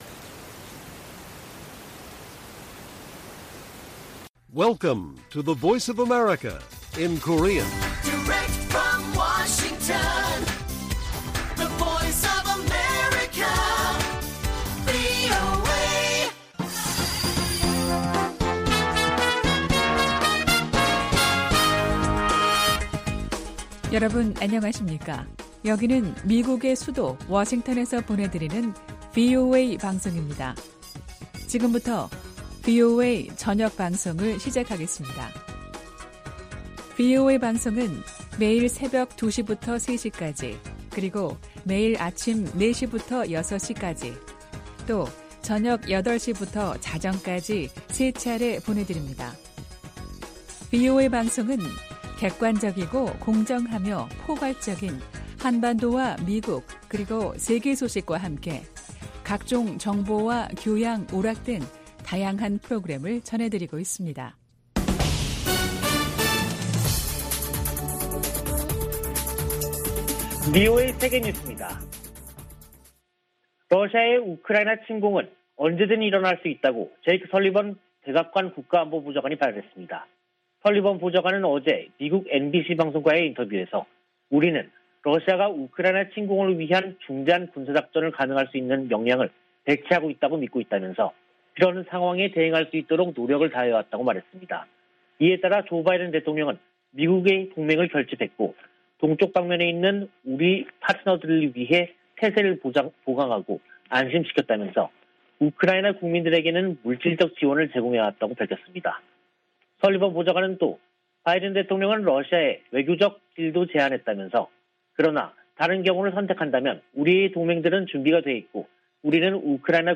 VOA 한국어 간판 뉴스 프로그램 '뉴스 투데이', 2022년 2월 7일 1부 방송입니다. 대니얼 크리튼브링크 미 국무부 동아시아 태평양 담당 차관보는 북한 위협 대응을 최우선 과제로 꼽았습니다. 미국의 핵·미사일 전문가들은 북한이 '공중 핵폭발' 역량을 확보한 것으로 분석했습니다. 유엔 안보리가 4일 북한의 잇따른 탄도미사일 발사 대응 관련 회의를 열었지만 규탄 성명 등을 위한 합의에 실패했습니다.